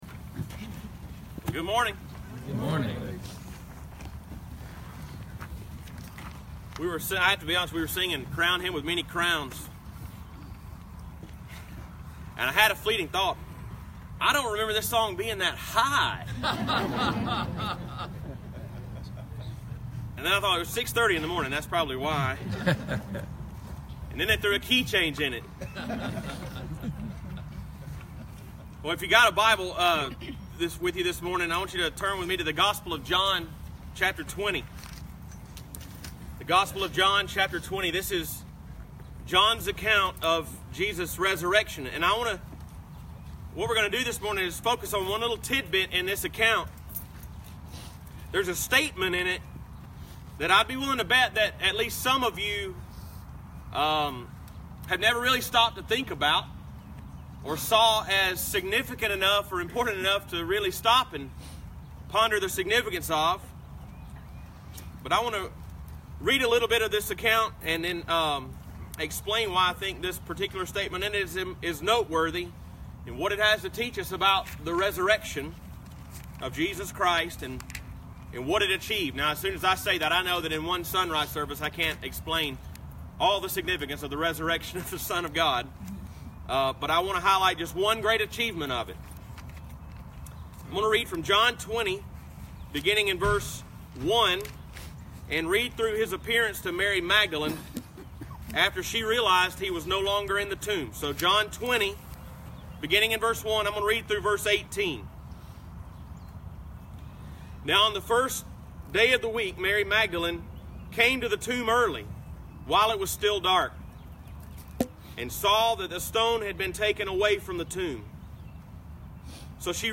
EASTER 2018 SUNRISE SERVICE